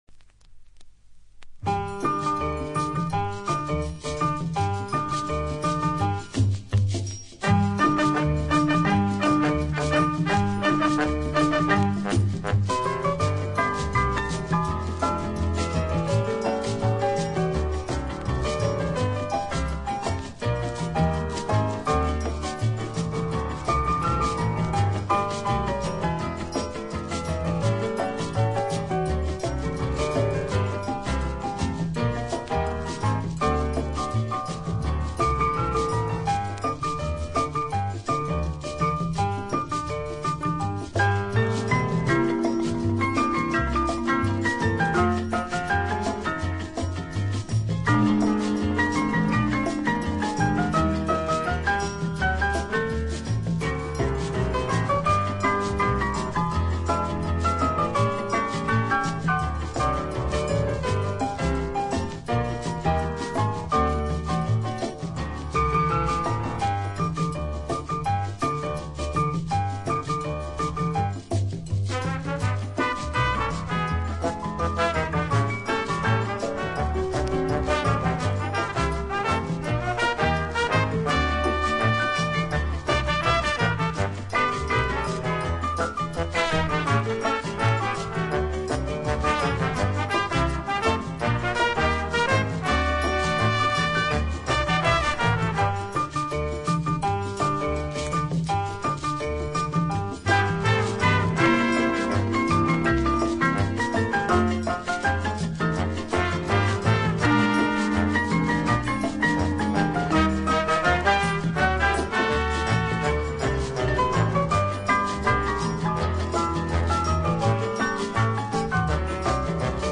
Genre:Jazz
Style:Latin Jazz, Easy Listening